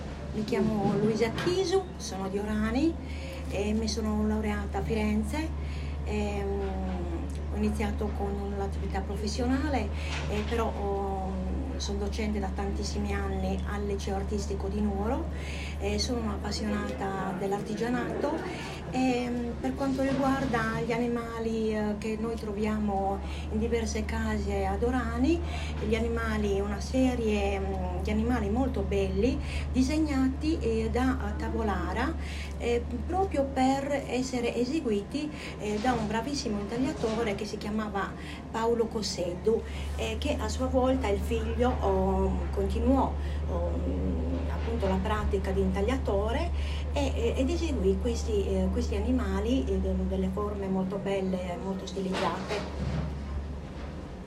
Luogo dell'intervista
Museo Nivola, Orani